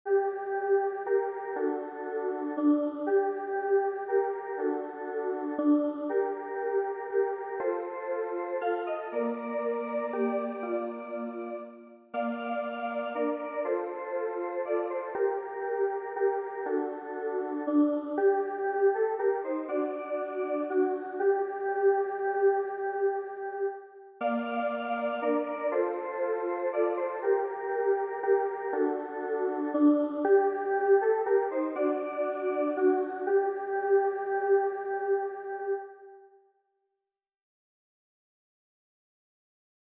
La m�lodie.
Envoyer un formulaire de recherche Berceuse cosaque Compositeur : Anonyme sur une po�sie de Lermontov Chant Traditionnel FAILED (the browser should render some flash content here, not this text).
berceuse_cosaque.mp3